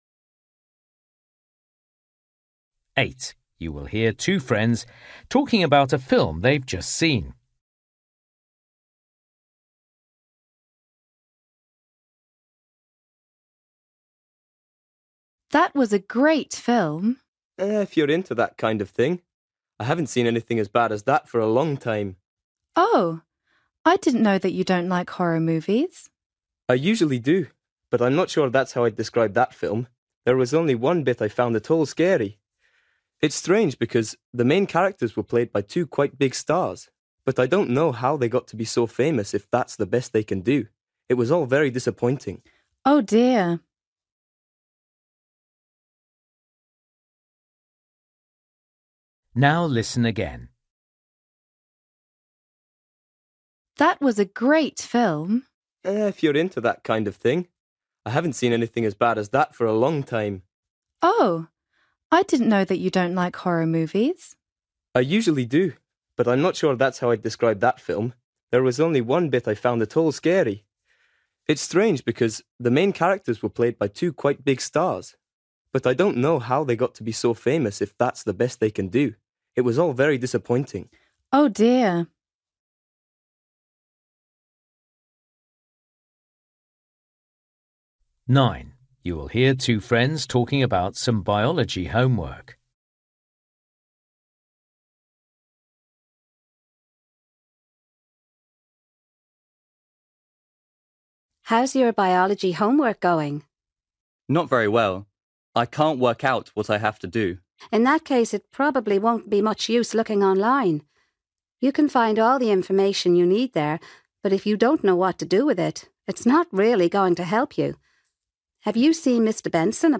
Listening: everyday short conversations
8   You will hear two friends talking about a film they’ve just seen. Why didn’t the boy enjoy the film?
11   You will hear a girl telling her friend about a diving trip. How did the girl feel about it?